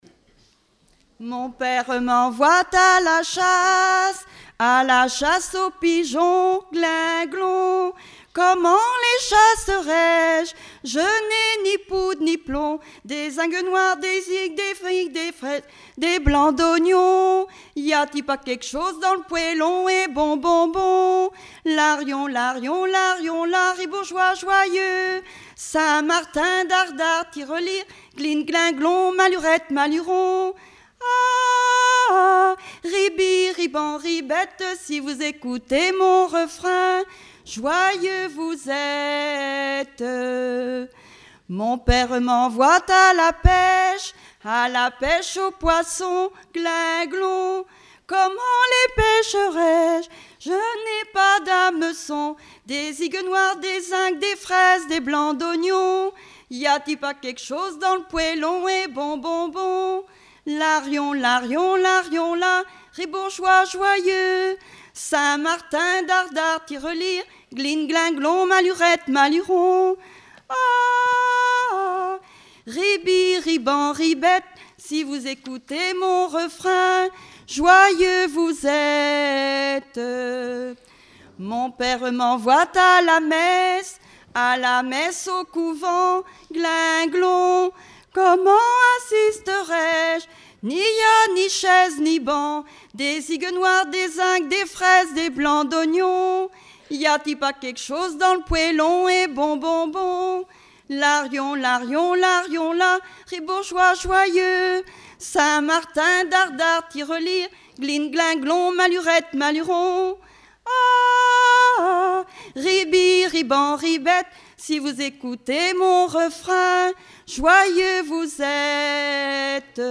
Genre laisse
Festival du chant traditionnel - 31 chanteurs des cantons de Vendée
Pièce musicale inédite